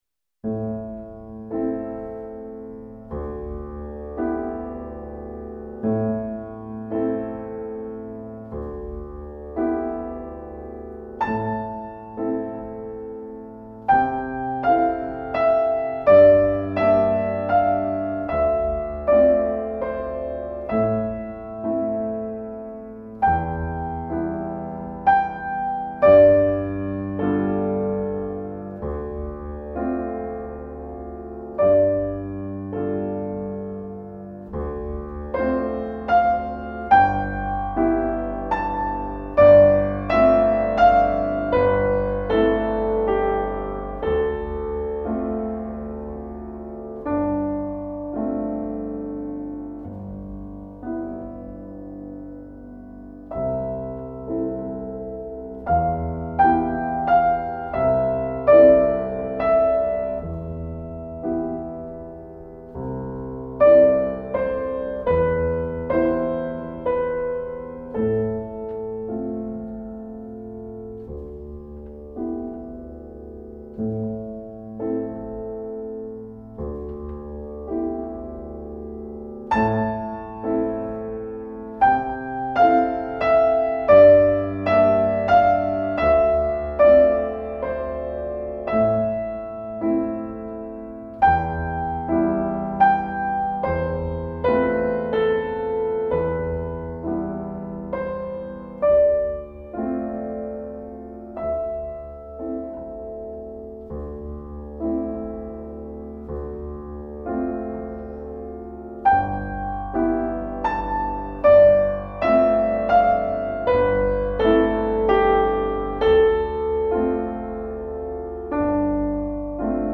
3. Lent et grave (A minor, 60 bars)
Instrumentation piano